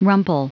Prononciation du mot rumple en anglais (fichier audio)
Prononciation du mot : rumple